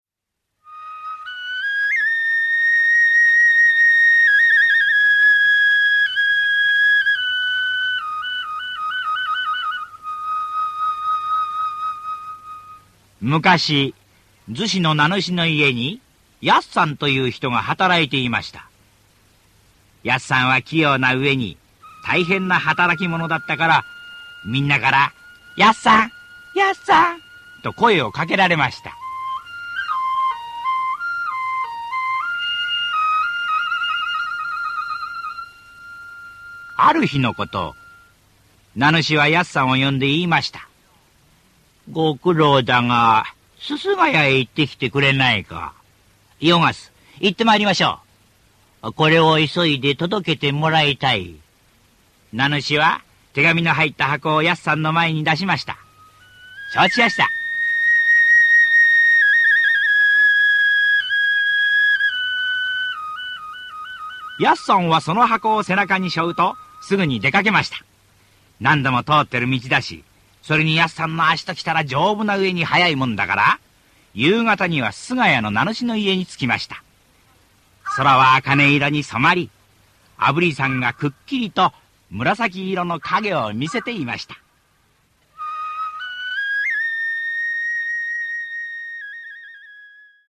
[オーディオブック] おくりおおかみ